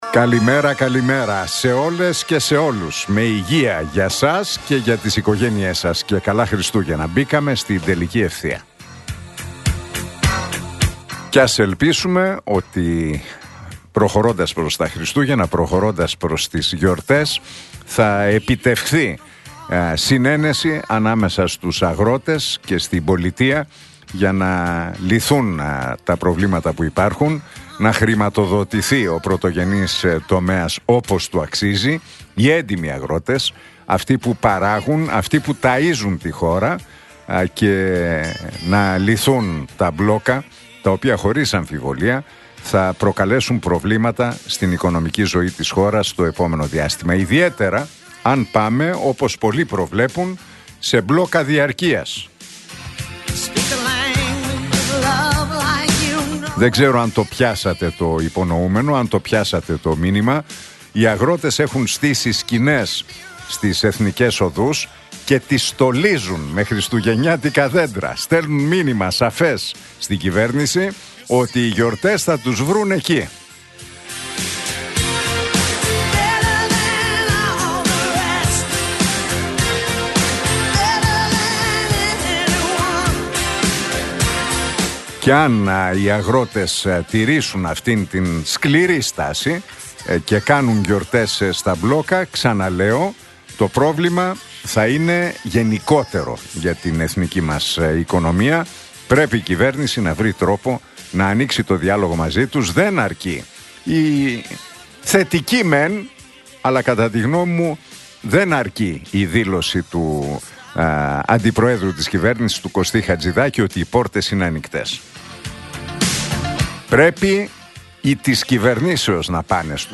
Ακούστε το σχόλιο του Νίκου Χατζηνικολάου στον ραδιοφωνικό σταθμό Realfm 97,8, την Τρίτη 2 Δεκεμβρίου 2025.